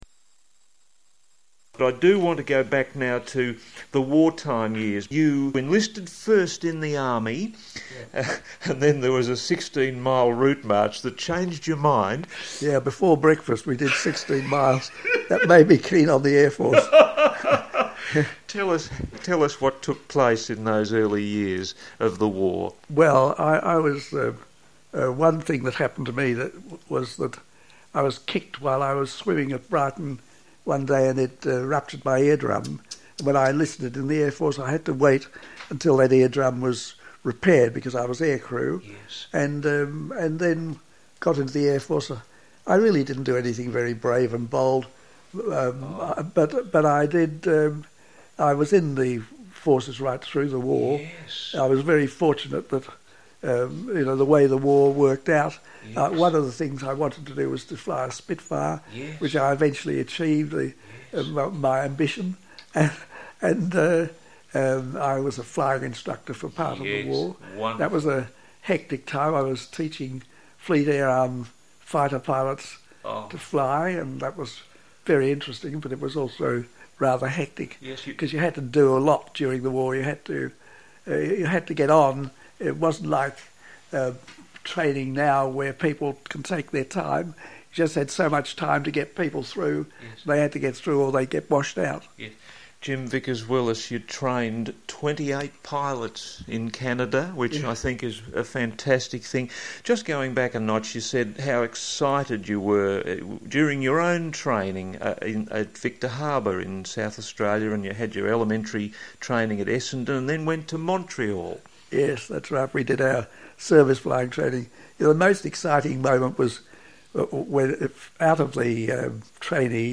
on Radio 98.1 FM about his service during World War II